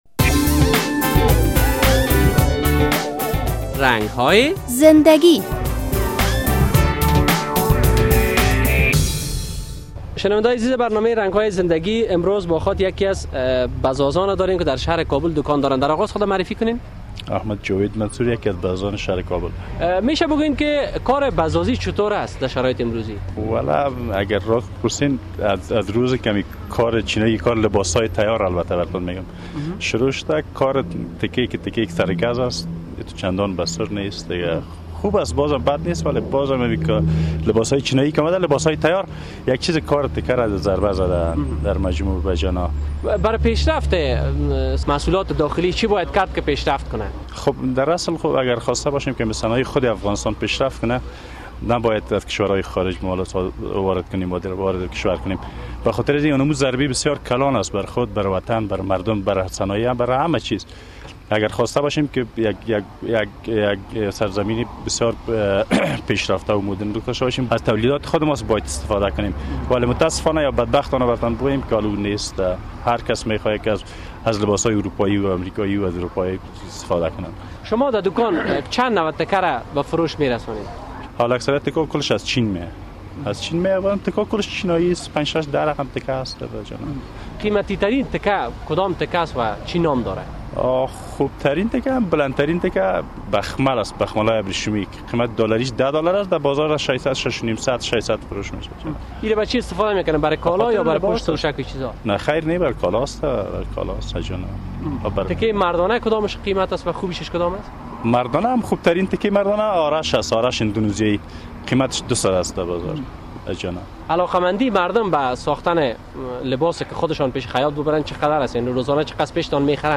در این برنامهء رنگ های زنده گی با یک تن از بزازان شهر کابل صحبت شده و در مورد کار بزازی در شهر کابل از وی سوالاتی مطرح شده است.